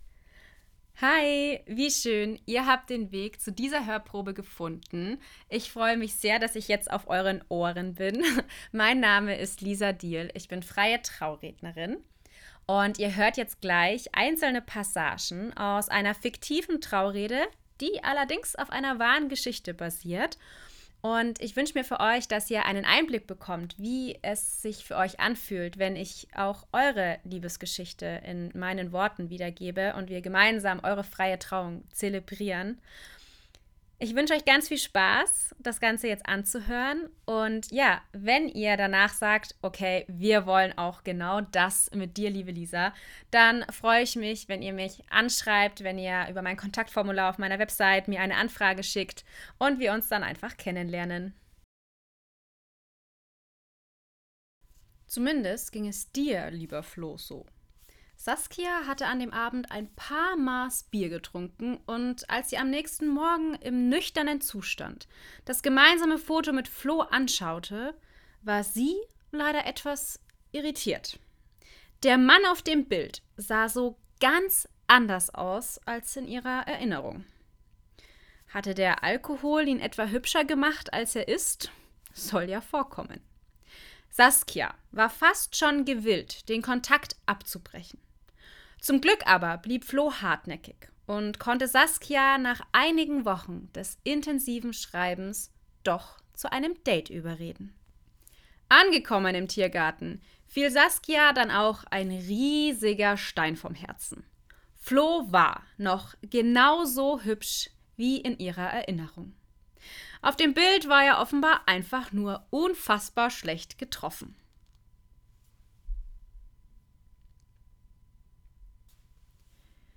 Neben dem, dass wir auf einer Wellenlänge sind, sollte euch meine Stimme und meine Art zu sprechen zu 100 % zusagen.
Und genau aus diesem Grund habe ich euch einige Passagen einer fiktiven Traurede, die jedoch auf einer wahren Liebesgeschichte basiert, aufgenommen.